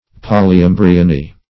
Search Result for " polyembryony" : The Collaborative International Dictionary of English v.0.48: Polyembryony \Pol`y*em"bry*o*ny\, n. [See Poly- , and Embryo .]
polyembryony.mp3